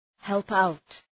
help-out.mp3